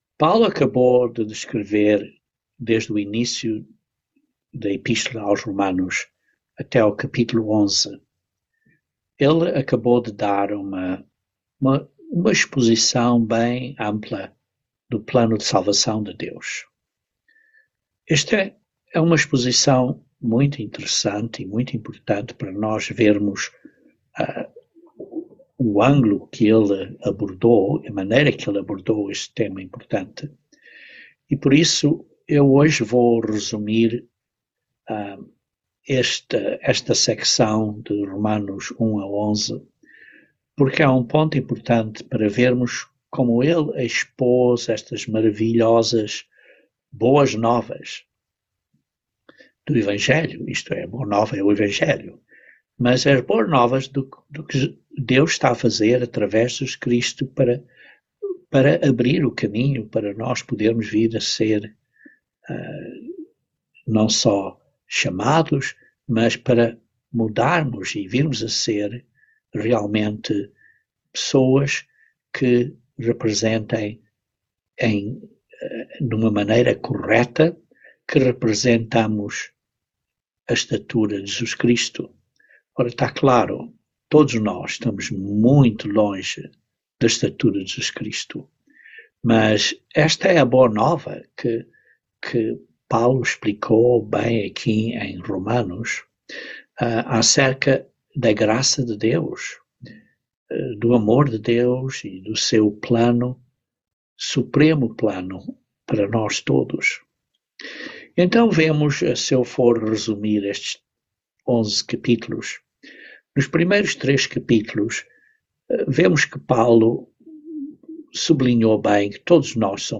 Romanos 12:1-2 - Estudo Bíblico